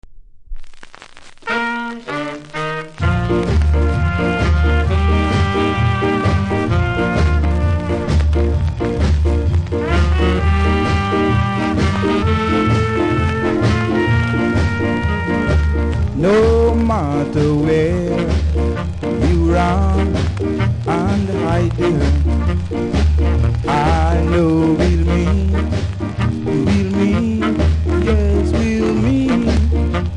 キズ、摩耗多めでそれなりにノイズ感じます。